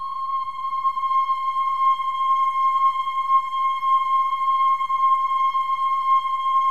OH-AH  C6 -L.wav